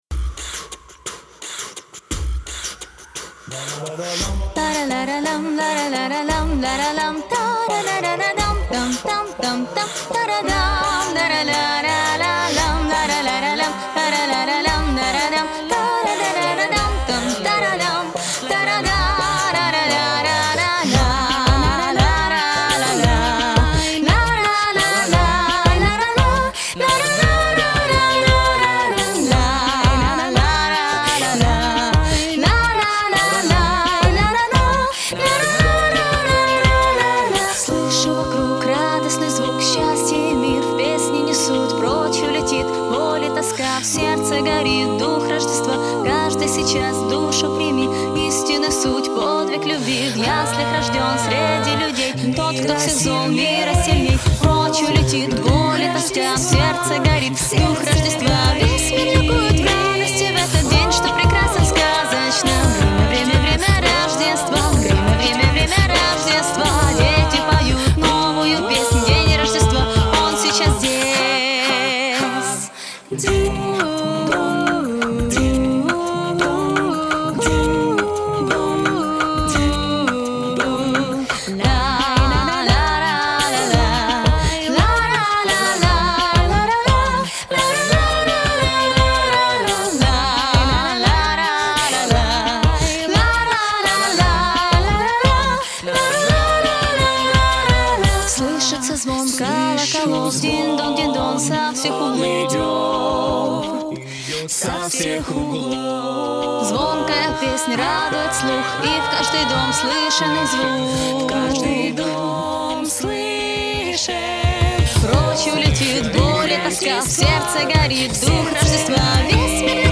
Duhovnyj_centr_Vozrozhdenie_Akapelnoe_penie___Vremya_Rozhdestva_2017.mp3